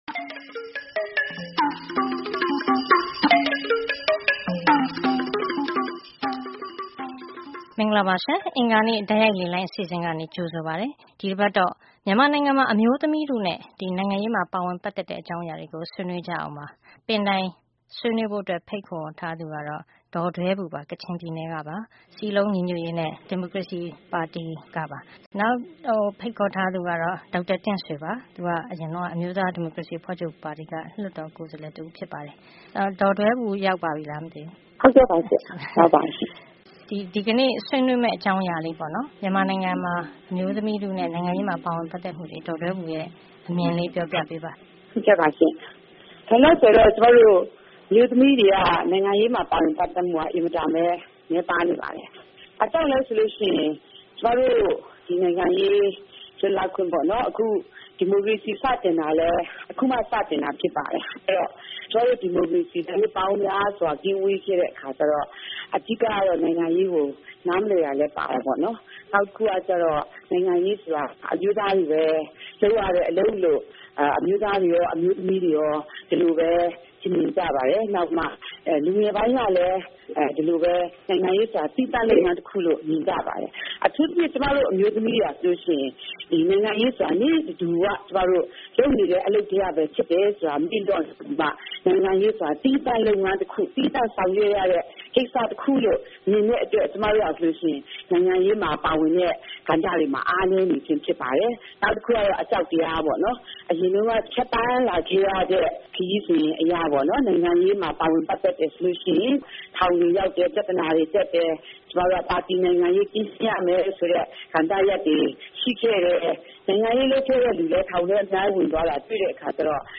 09-01-15 Tuesday Call in Show - Burmese women and politics
09-01-15 အင်္ဂါ တိုက်ရိုက်လေလှိုင်း ဆွေးနွေးခန်း -မြန်မာအမျိုးသမီးများနဲ့ နိုင်ငံရေး